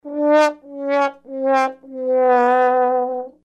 Sad Trombone.....TUNE